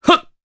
Kasel-Vox_Jump_jp.wav